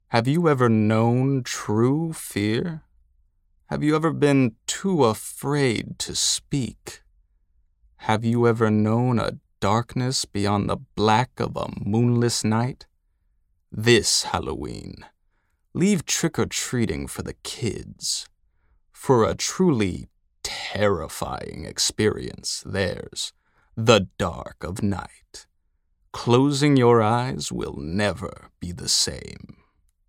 Male
Yng Adult (18-29)
Radio Commercials
Words that describe my voice are Versatile, Calm, Natural.